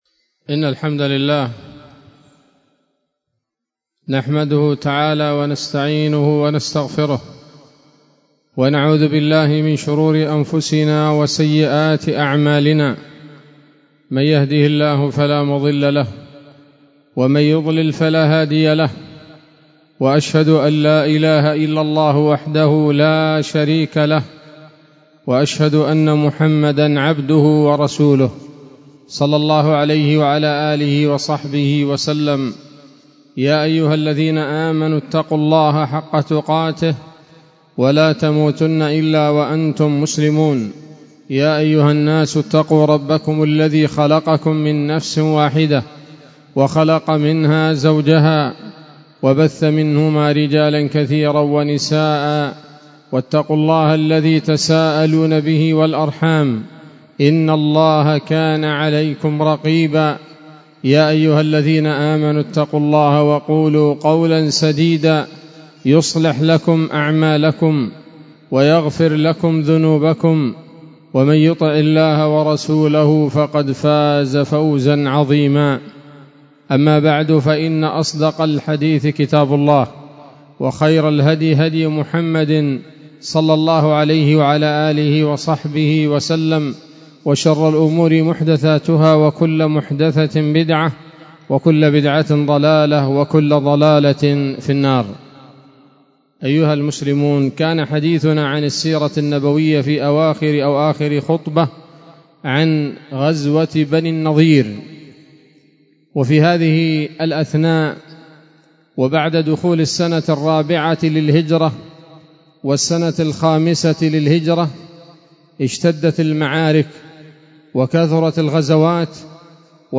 خطبة جمعة بعنوان: (( السيرة النبوية [20]